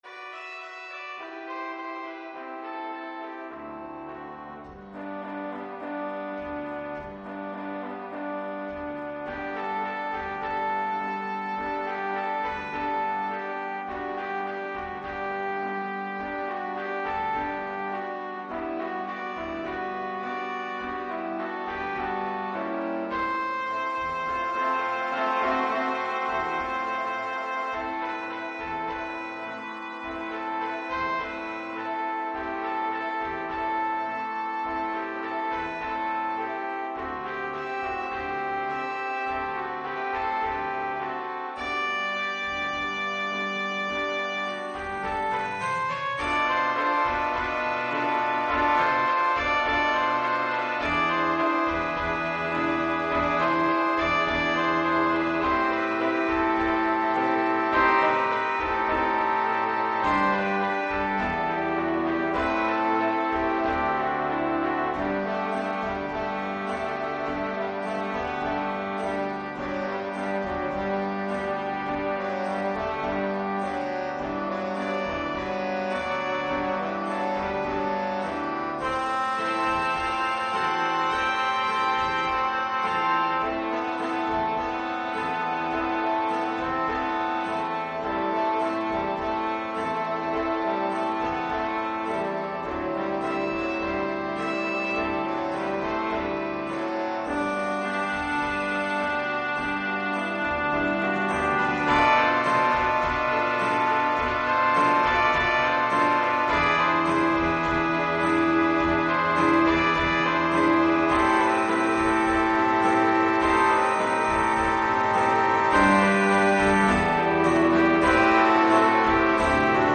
Gattung: 5-Part Ensemble
Besetzung: Ensemble gemischt
keyboard, Guitar, Drums & Percussions optional.